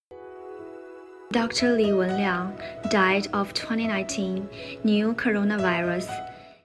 Forresten. Her er hvordan man udtaler Li Wenliang:
pronounceliwenliang.mp3